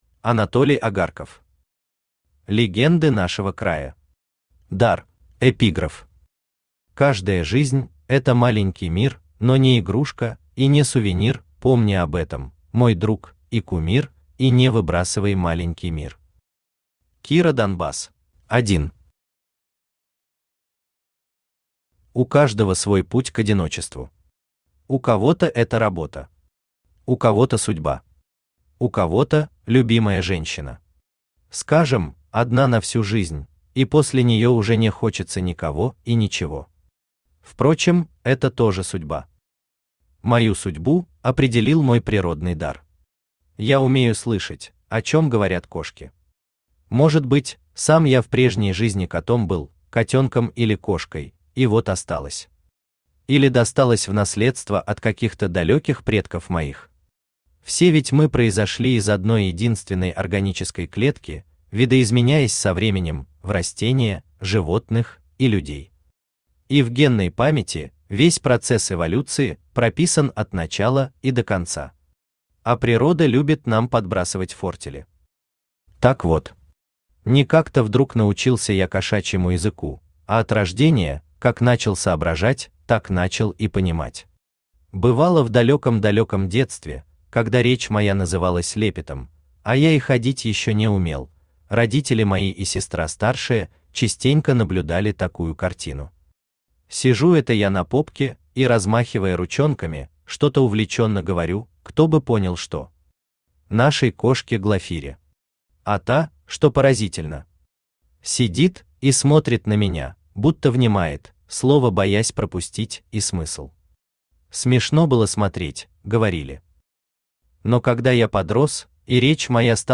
Аудиокнига Легенды нашего края. Дар | Библиотека аудиокниг
Дар Автор Анатолий Агарков Читает аудиокнигу Авточтец ЛитРес.